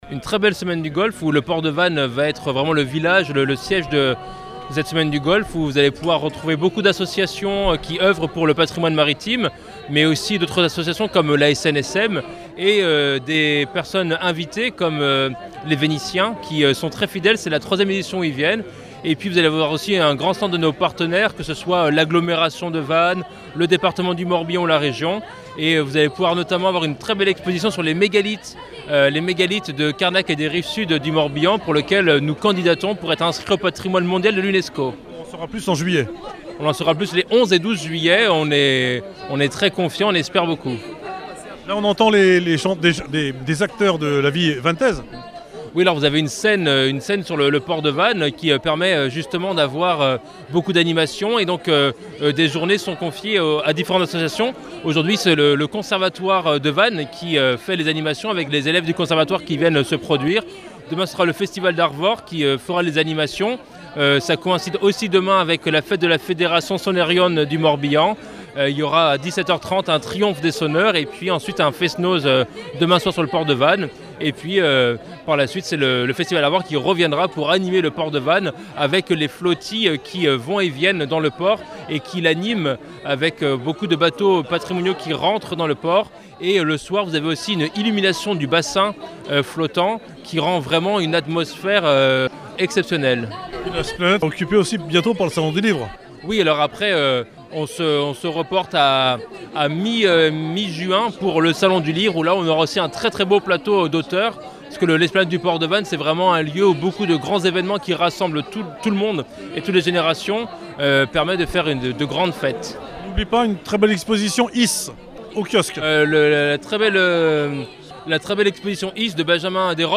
10 ème édition depuis 2001 / Présentation  et Interviews :
Fabien Le Guernevé – 1er Maire adjoint et à la culture